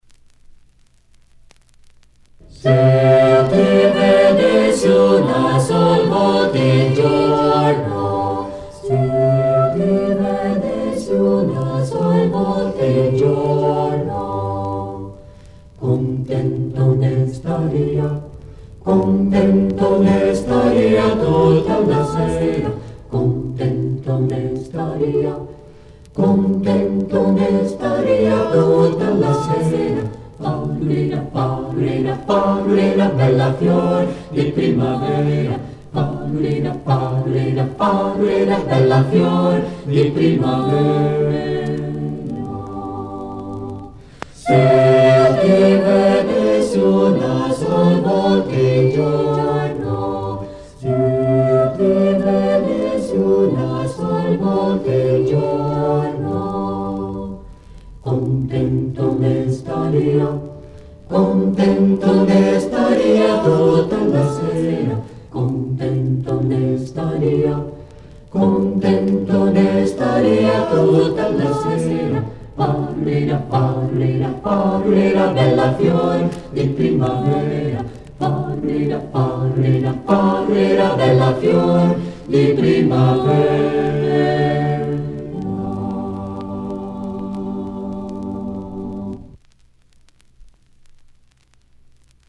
O Coral de Câmara Pró-Arte de Porto Alegre grava grandes obras da Renascença Universal.
5 – S’io ti vedessi  (O. di Lasso) – Renascença Flamenga –